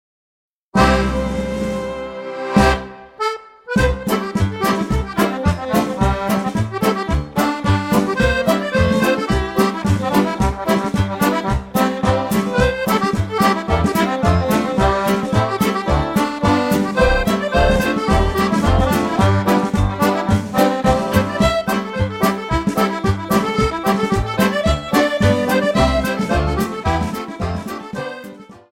8 x 32 Reel